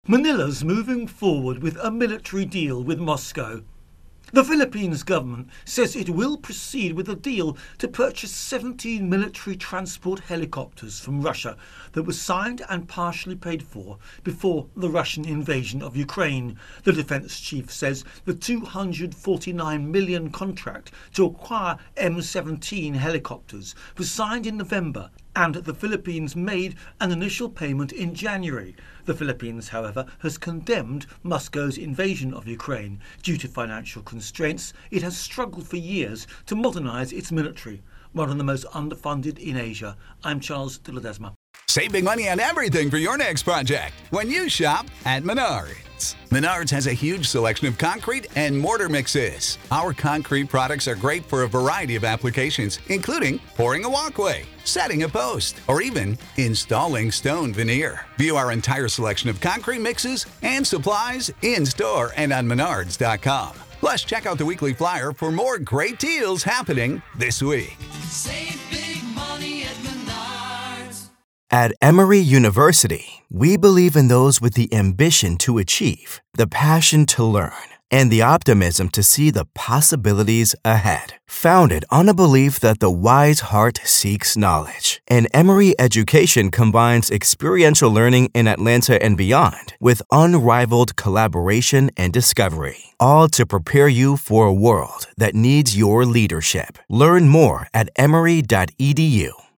Philippines-Russia-Helicopter Deal Intro and Voicer